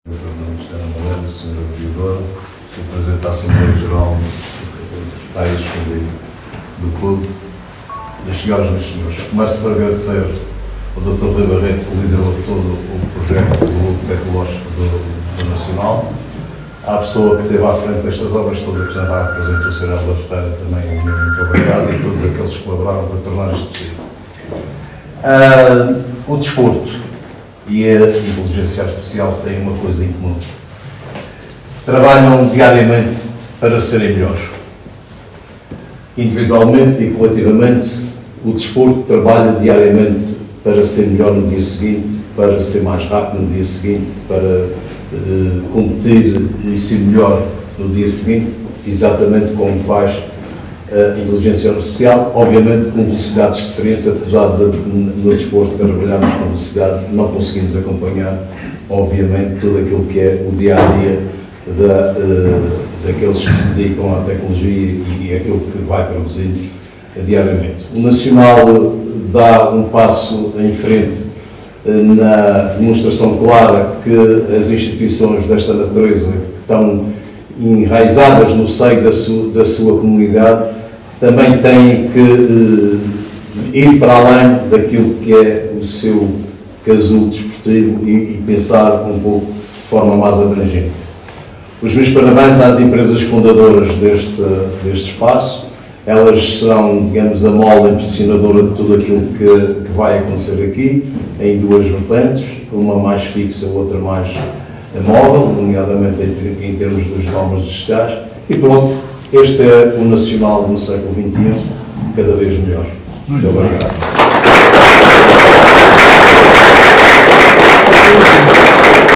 ÁUDIOS | discursos: